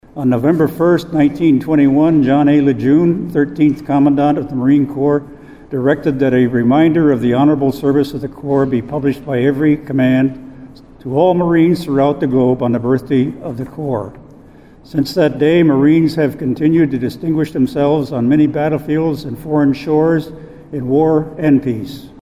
PIERRE, S.D.(DRGNews)-The United States Marine Corps turned 250 years old Monday and South Dakota marked the occasion with a ceremony and official cake cutting in Pierre.